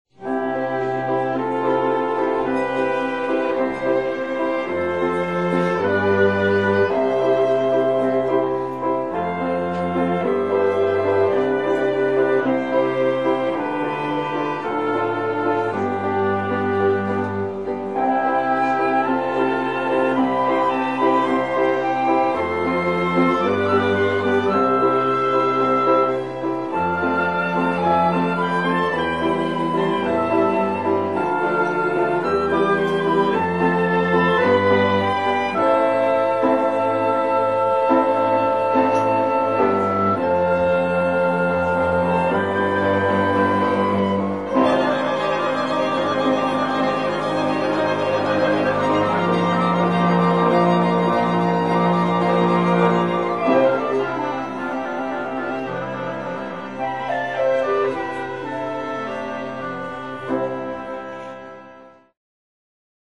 Flute、Recorder、Oboe、Clarinet、Violin、Cello、Piano